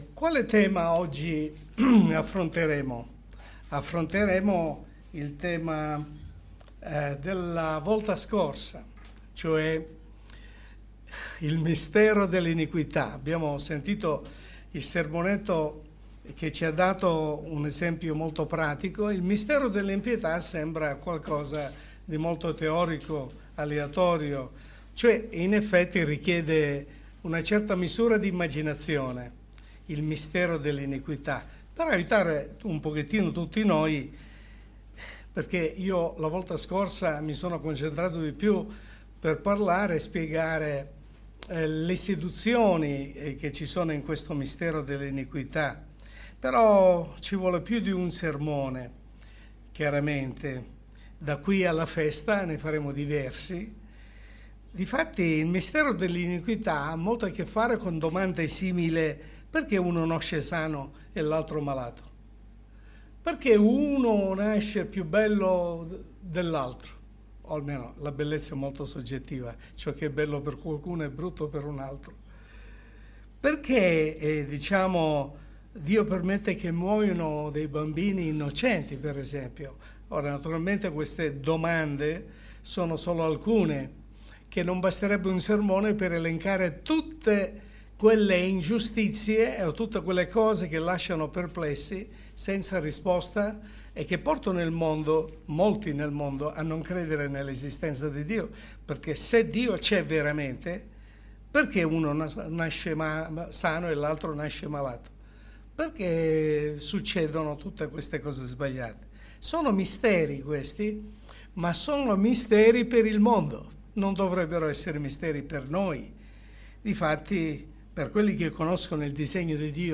Messaggio pastorale